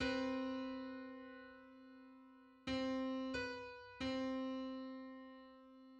File:Nine-hundred-sixty-first harmonic on C.mid - Wikimedia Commons
Public domain Public domain false false This media depicts a musical interval outside of a specific musical context.
Nine-hundred-sixty-first_harmonic_on_C.mid.mp3